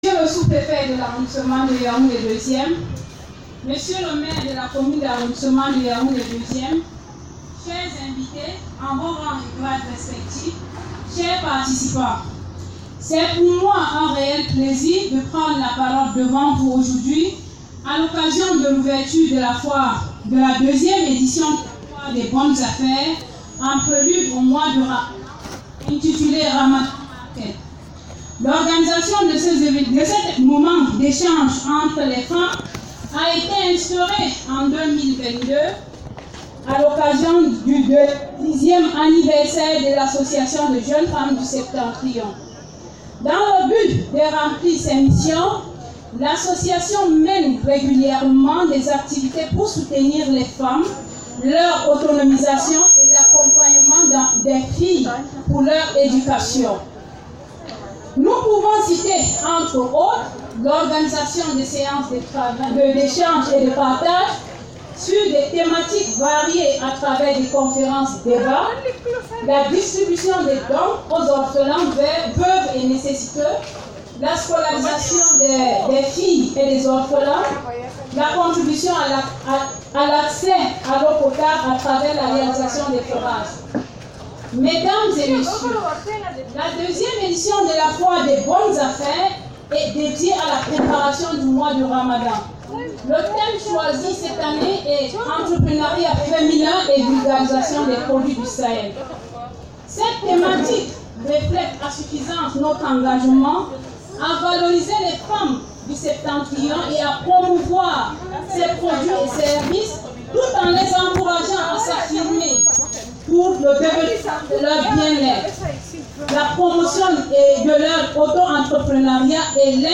La cérémonie inaugurale, empreinte de solennité, s’est tenue en présence des autorités locales, symbolisant l’adhésion institutionnelle à cette initiative en faveur de l’entrepreneuriat féminin.